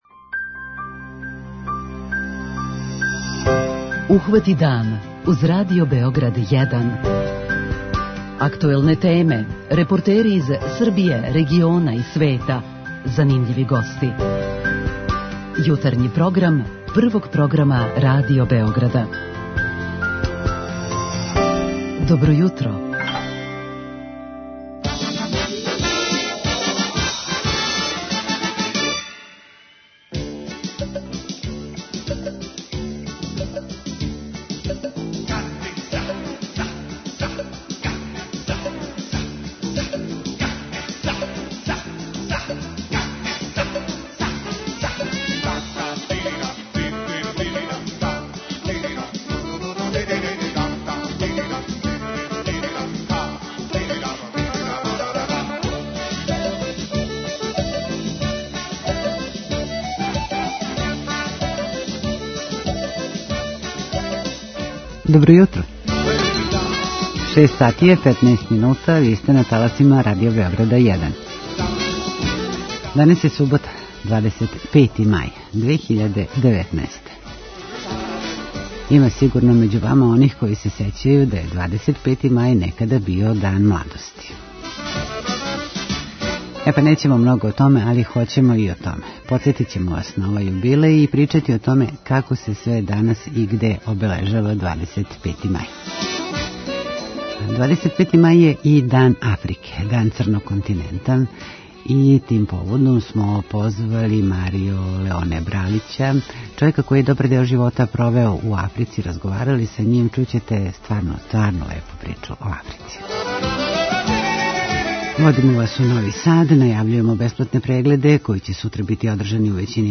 Некадашњи Дан младости данас ћемо почети редовним информацијама Јутарњег програма: доносимо репортажу из Радарског центра Букуља, причу о Дунавским острвима код Панчева, најављујемо манифестације у Новом Саду.